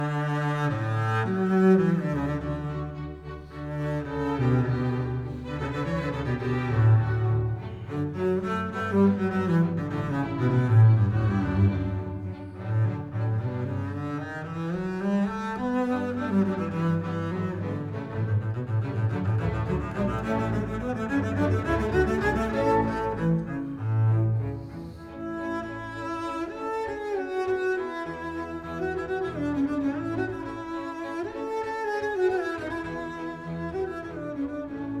Classical
Жанр: Классика